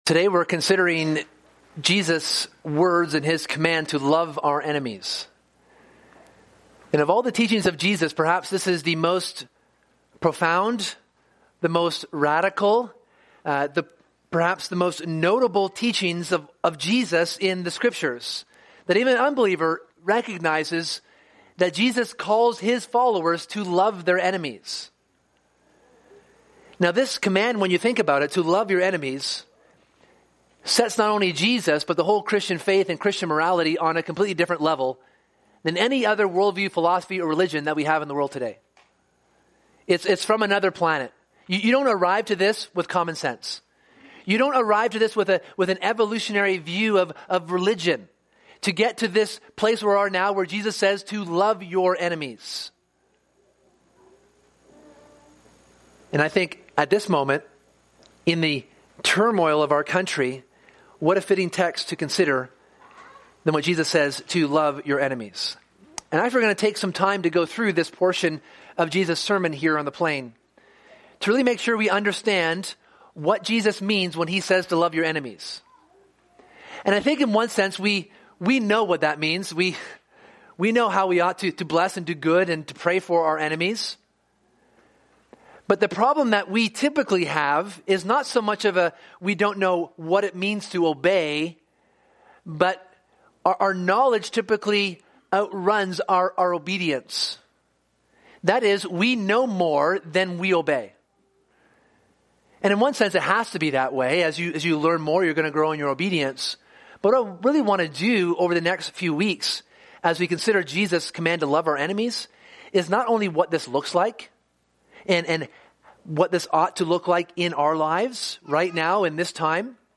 This sermon considers what it means to love our enemies and how we can do that in the grace that God provides. Here is a call to be other-worldly in our dealings with our opponents.